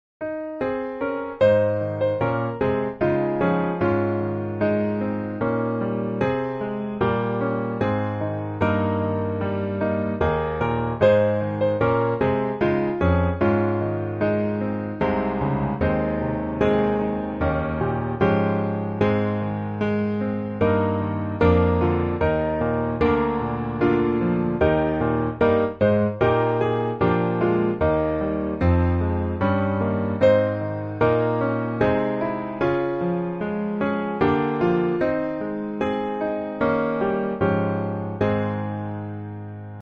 Ab Major